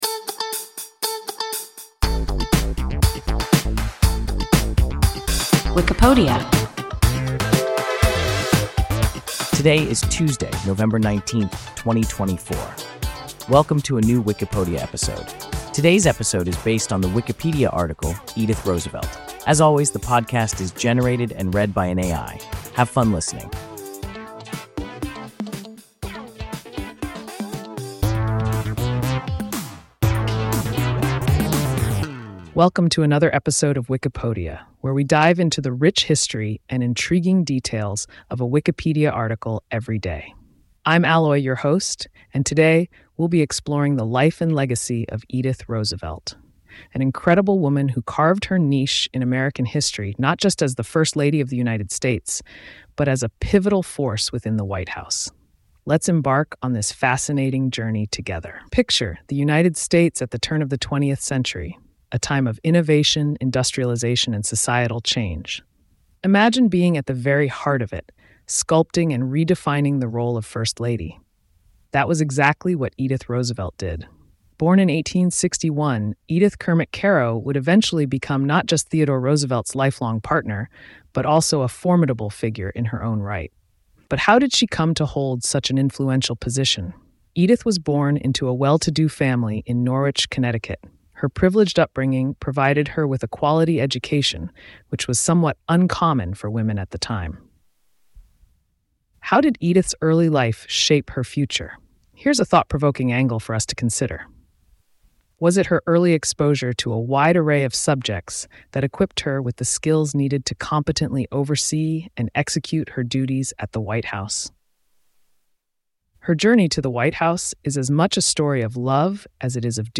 Edith Roosevelt – WIKIPODIA – ein KI Podcast
Wikipodia – an AI podcast